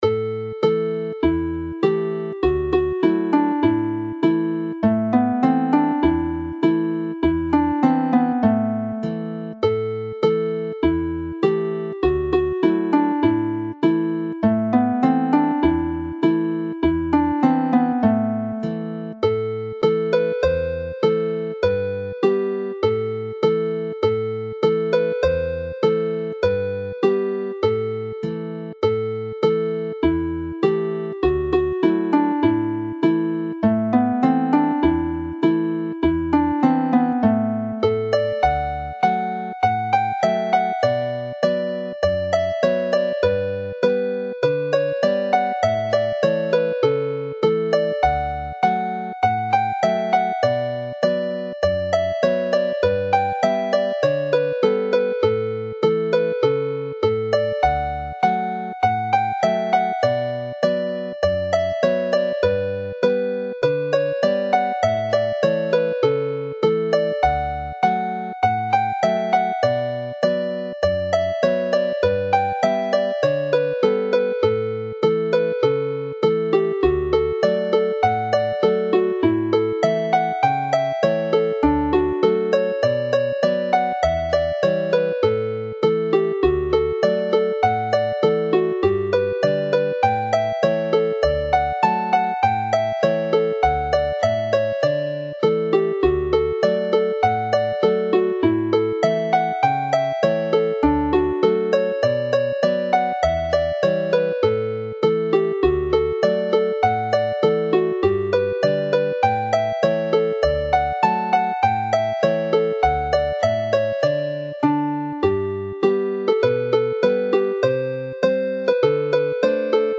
Alawon Cymreig - Set Dowlais - Welsh folk tunes to play
Play the set slowly